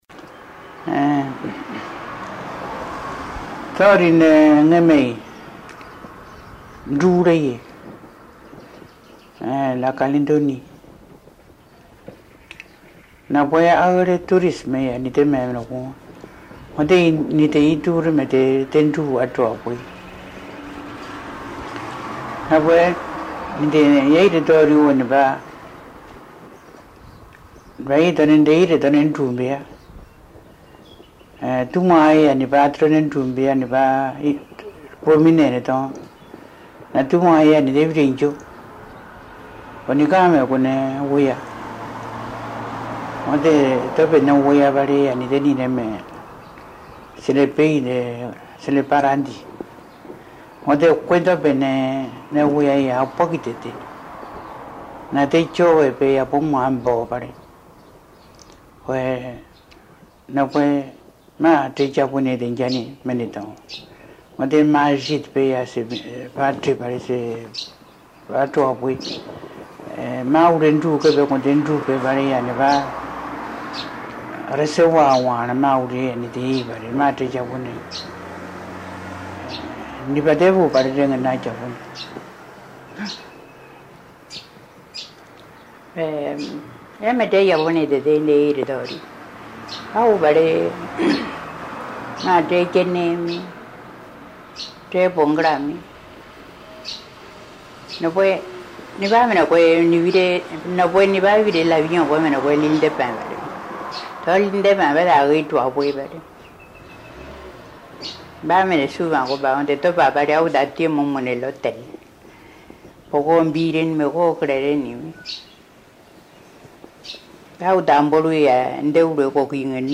Documents joints Dialogue leçon 42 ( MP3 - 3.2 Mio ) Un message, un commentaire ?